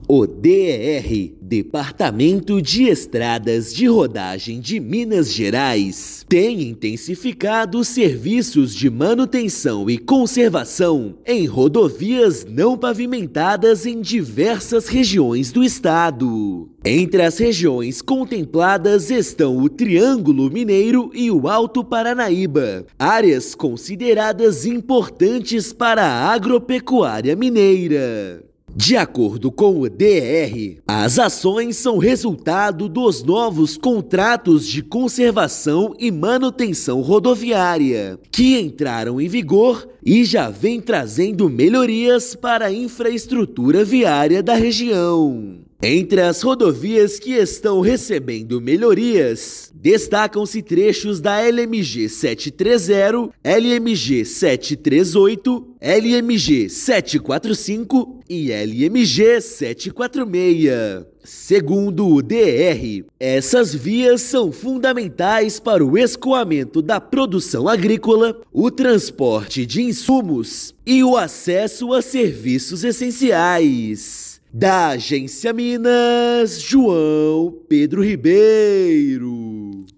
Intervenções nas LMGs 730, 738, 745 e 746 melhoram o escoamento da produção agropecuária e fortalecem a economia local. Ouça matéria de rádio.